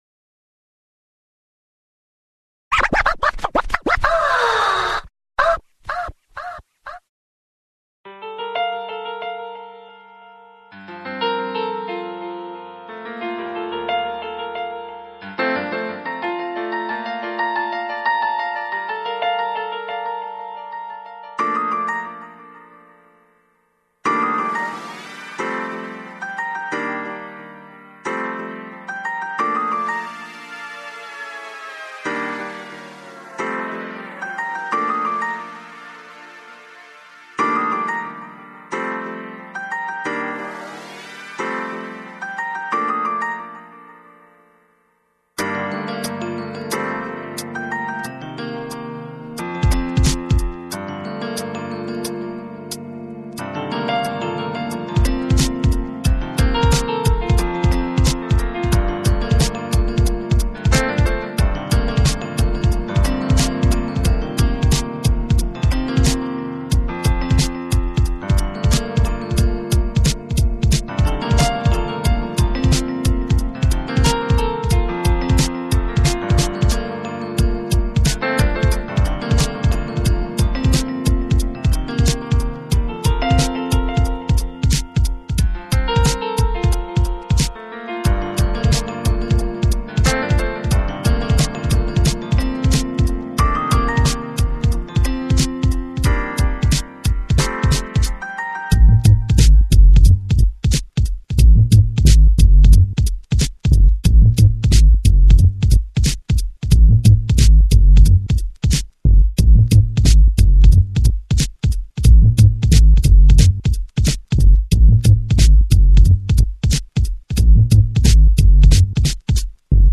2006 Электронная